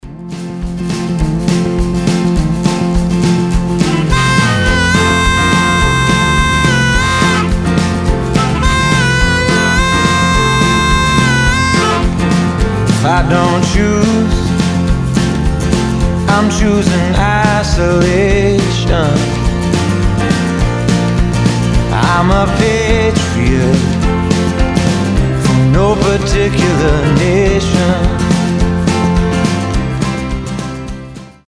фолк
губная гармошка
акустика
кантри